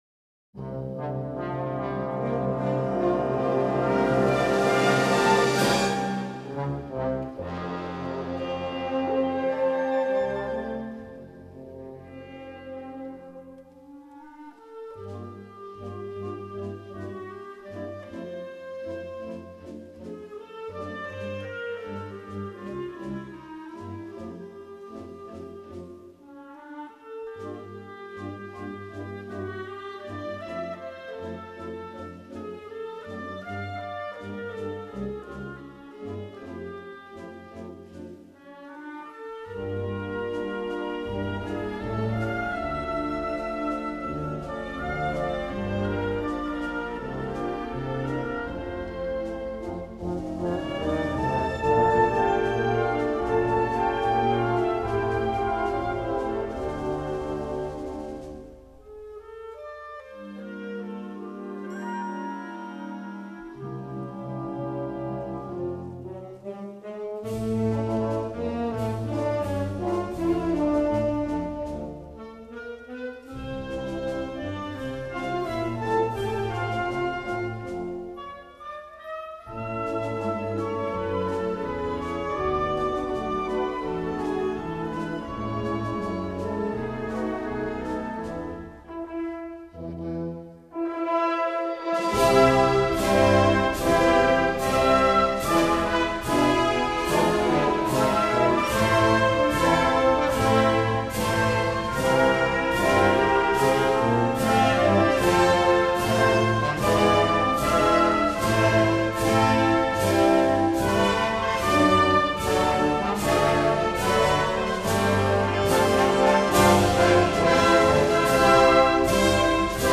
Partituras de música Pasodoble para Orquestas y bandas.
Género: Pasodoble